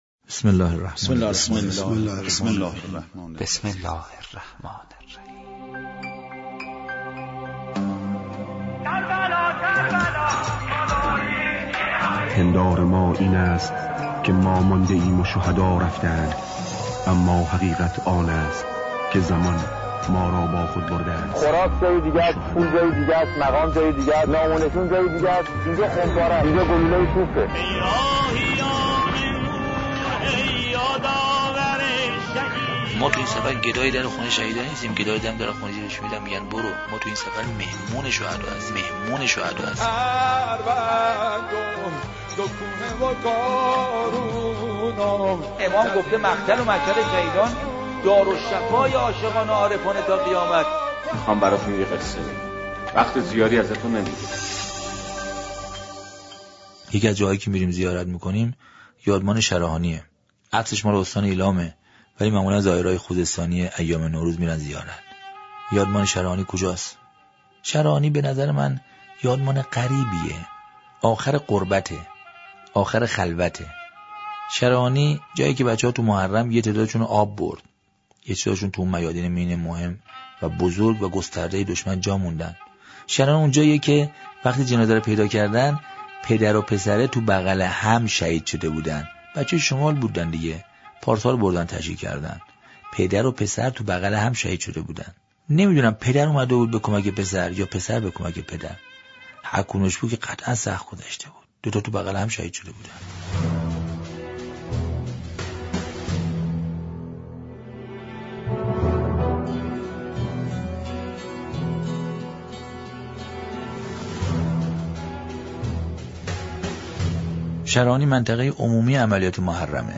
این صوت روایتی دلنشین از یادمان شرهانی می‌باشد .شرهانی سرزمین عملیات محرم است که هم اکنون یکی از یادمان‌های زیبای دفاع مقدس نام‌برده می‌شود.